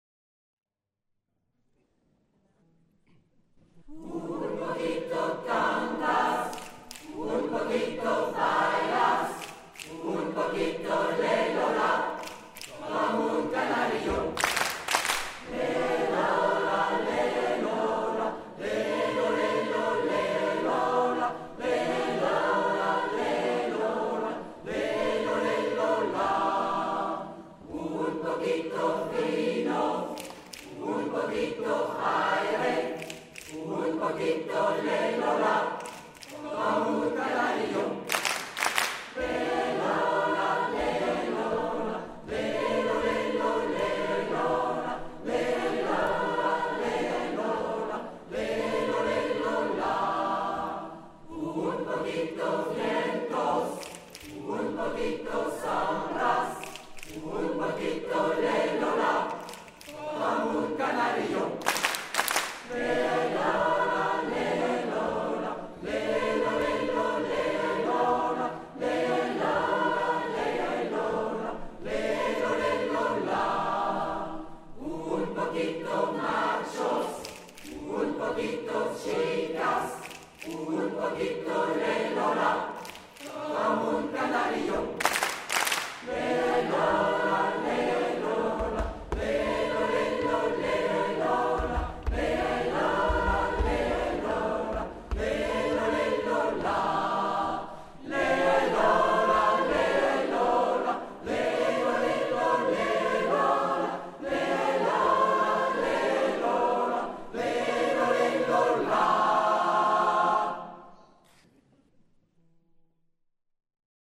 - Enregistrements de concerts 2011/2012 au format MP3 (fichier Croqnotes_2011-2012.zip à télécharger)